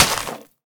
Minecraft Version Minecraft Version snapshot Latest Release | Latest Snapshot snapshot / assets / minecraft / sounds / block / muddy_mangrove_roots / break5.ogg Compare With Compare With Latest Release | Latest Snapshot